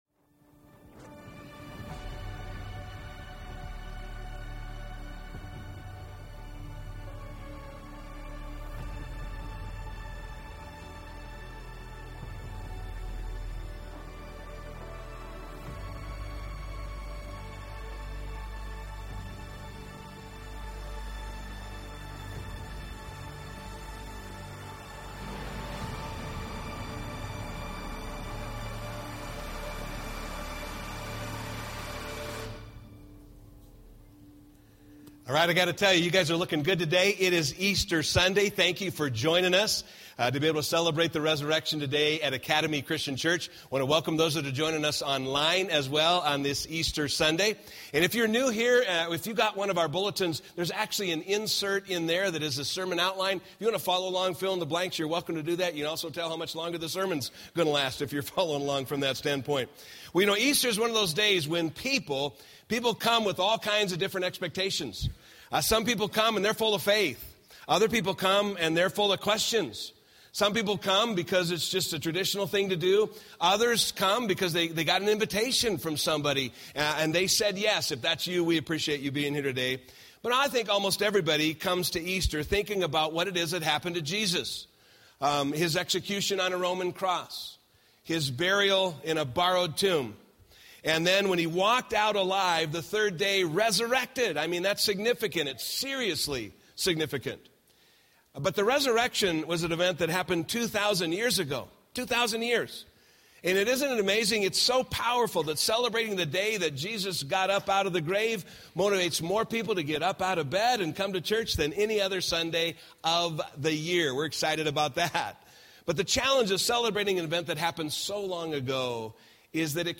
A collection of ACC Sunday Messages that are not a part of a Sermon Series